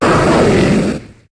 Fichier:Cri 0244 HOME.ogg — Poképédia
Cri d' Entei dans Pokémon HOME .